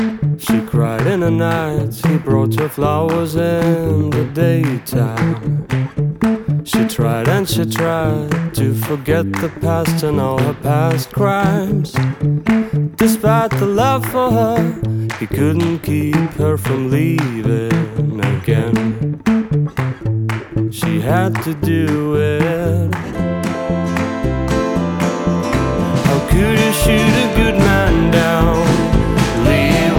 balss, taustiņinstrumenti
ģitāra, balss
bungas, perkusijas
basģitāra, ģitāra un citi instrumenti